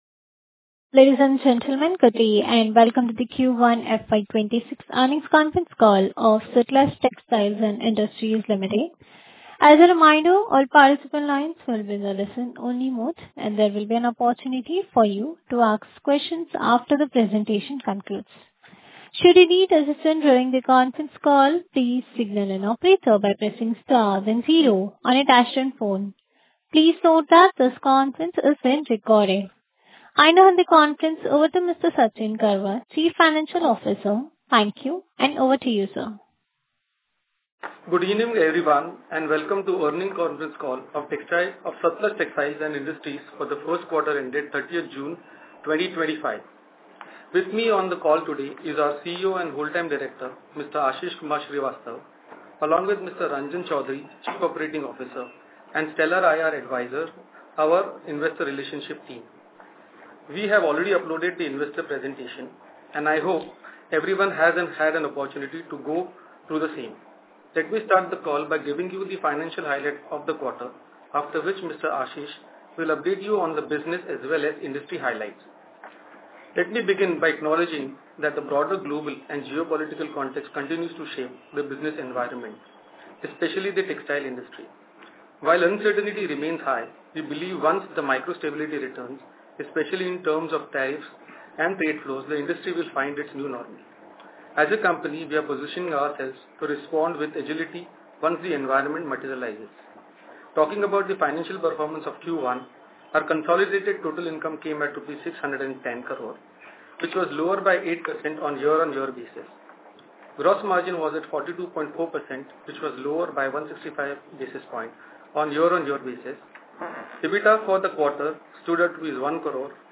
SutlejTextiles_Q1FY26EarningsCallAudio.mp3